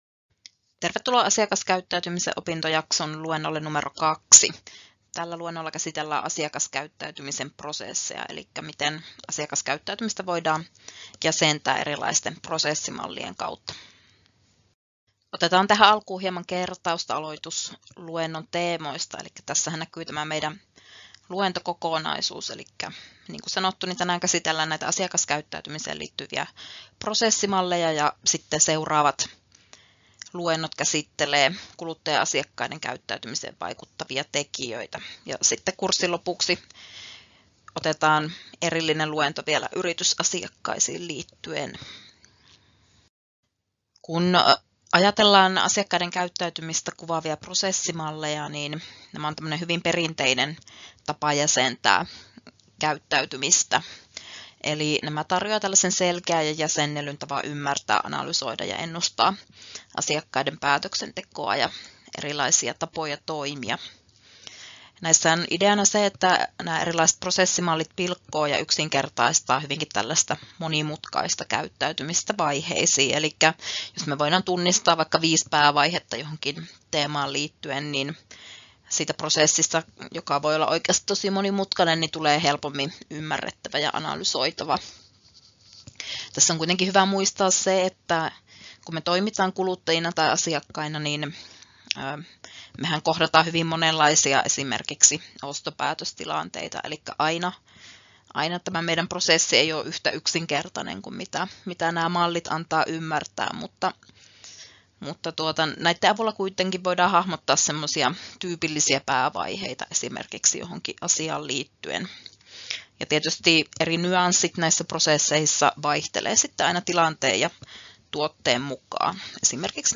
Luento 2: Asiakaskäyttäytymisen prosessit — Moniviestin